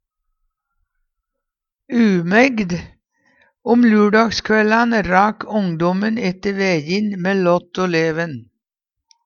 DIALEKTORD PÅ NORMERT NORSK umægd ungdom Eksempel på bruk Om lurdagskvællan rak umægde ette væjin mæ lått o leven.